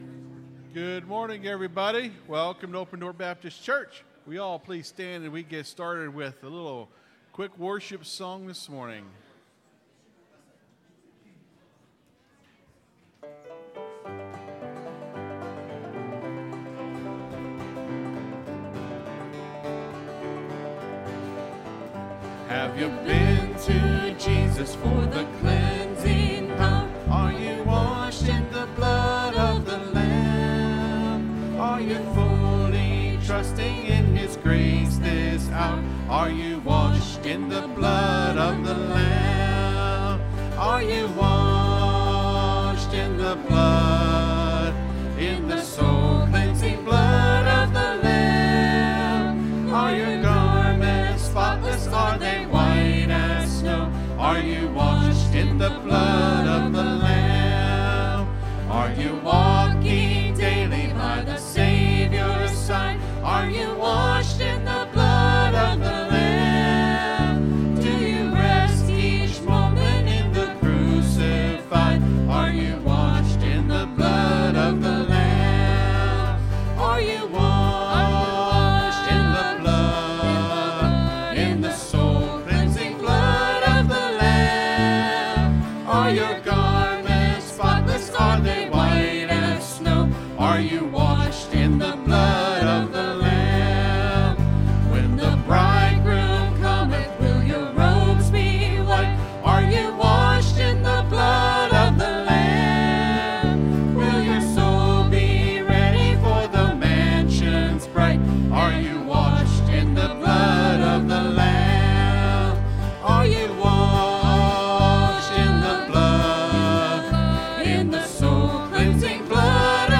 (Sermon starts at 24:00 in the recording).
(Due to a power outage at the church at the 35-minute mark in the recording, there is a 5-10-minute portion of the sermon that was not recorded.)